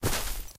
328d67128d Divergent / mods / Soundscape Overhaul / gamedata / sounds / material / human / step / tmp_default4.ogg 19 KiB (Stored with Git LFS) Raw History